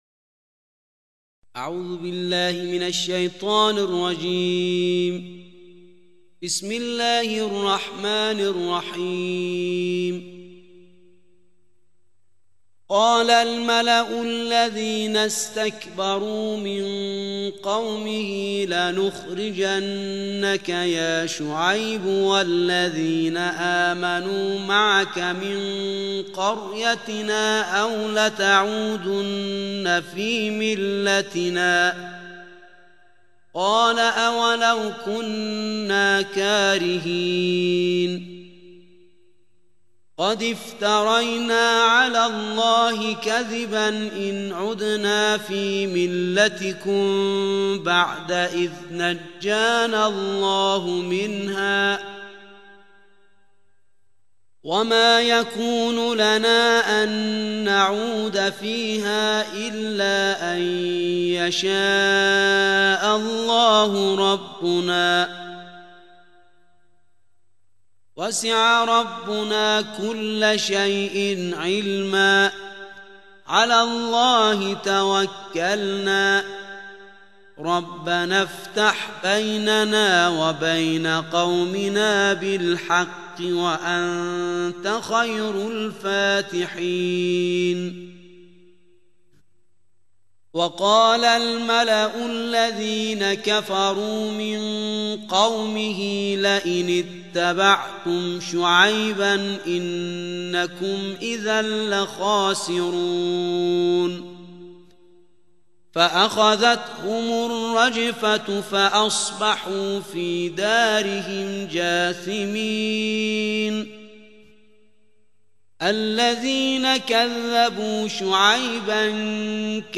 صوت تلاوت ترتیل جزء نهم قرآن کریم